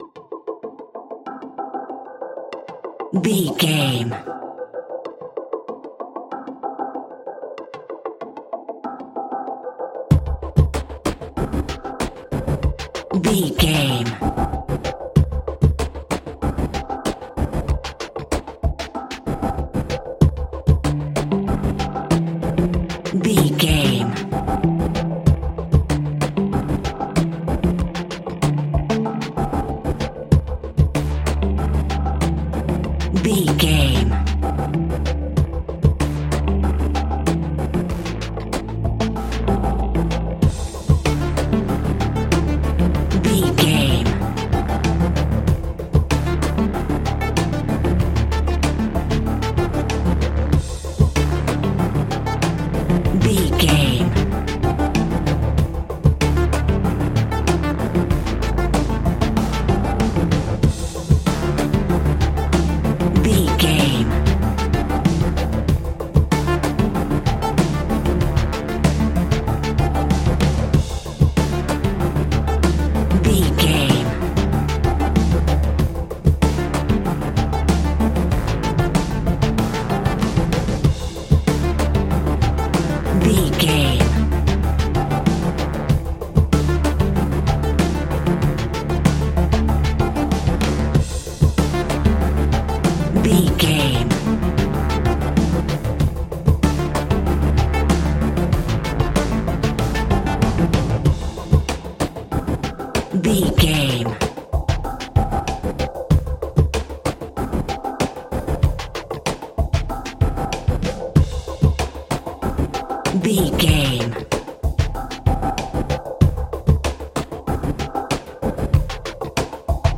Aeolian/Minor
piano
synthesiser